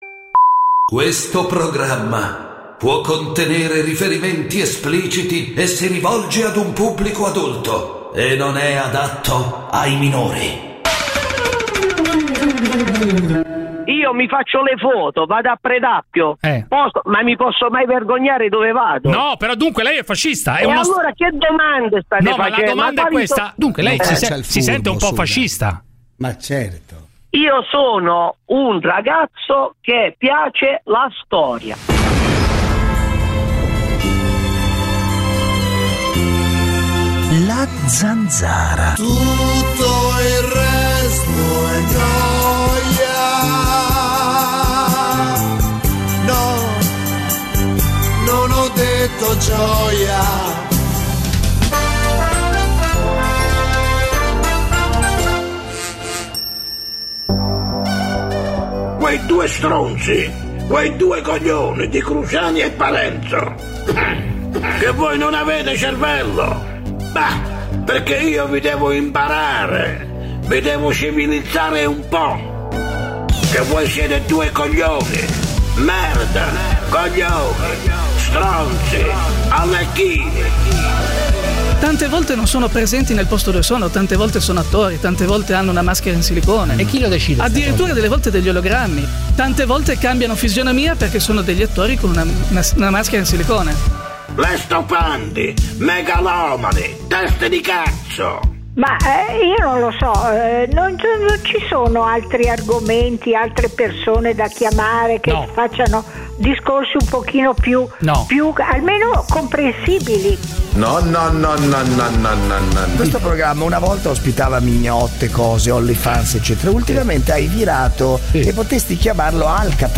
… continue reading 1898 epizódok # Italia Attualità # News Talk # Notizie # Radio 24